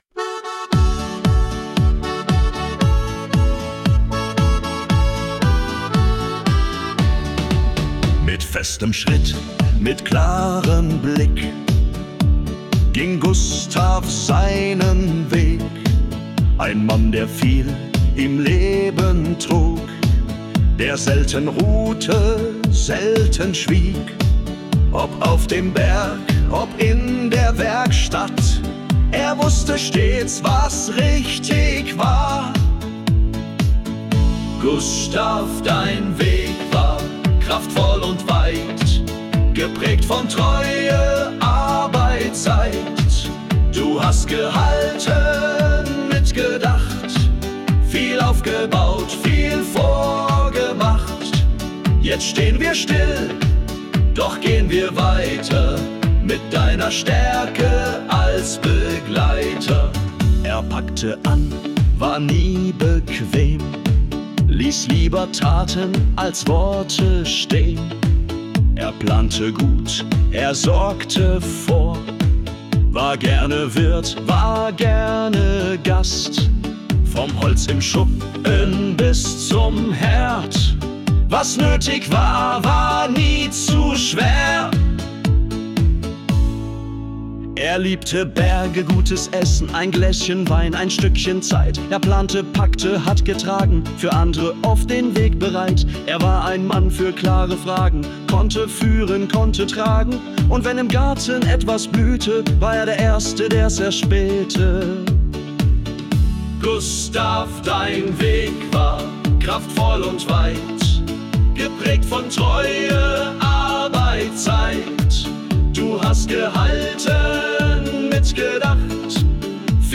Volksmusik